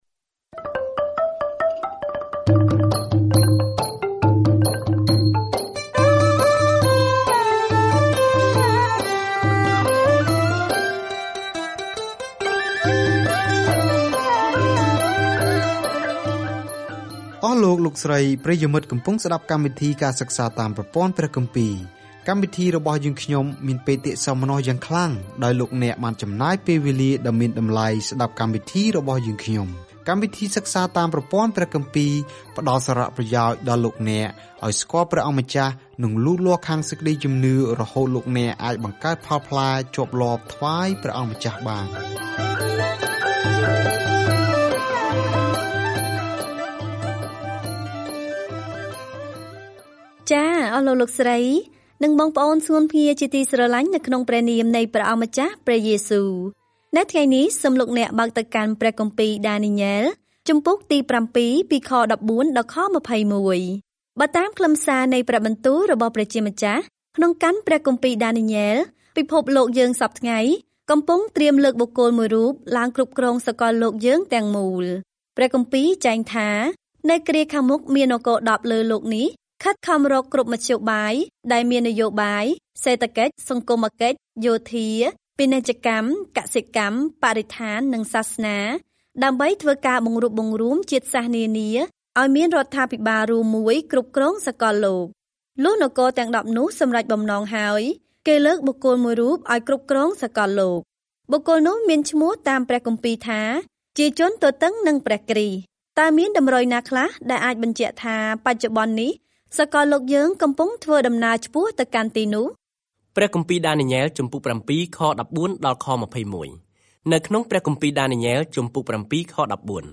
សៀវភៅដានីយ៉ែលគឺជាសៀវភៅជីវប្រវត្តិរបស់បុរសម្នាក់ដែលបានជឿព្រះ និងជាទស្សនវិស័យព្យាករណ៍អំពីអ្នកដែលនឹងគ្រប់គ្រងពិភពលោកនៅទីបំផុត។ ការធ្វើដំណើរជារៀងរាល់ថ្ងៃតាមរយៈដានីយ៉ែល នៅពេលអ្នកស្តាប់ការសិក្សាជាសំឡេង ហើយអានខគម្ពីរដែលជ្រើសរើសពីព្រះបន្ទូលរបស់ព្រះ។